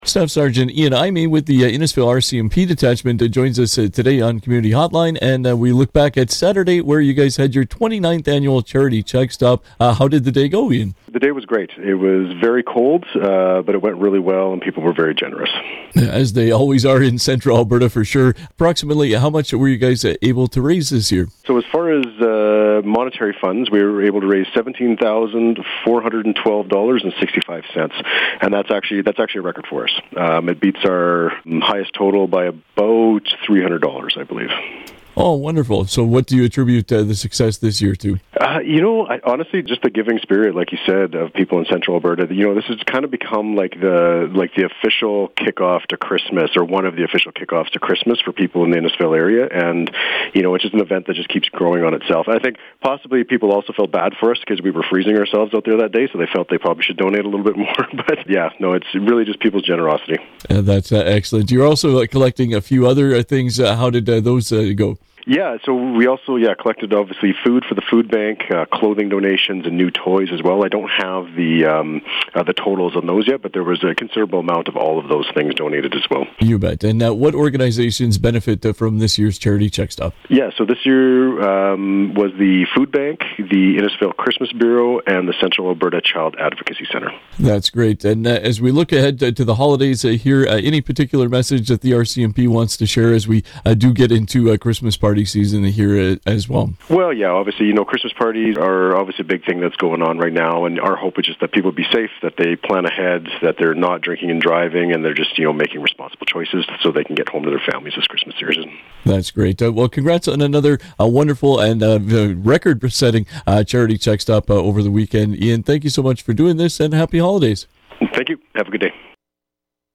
Community Hotline conversation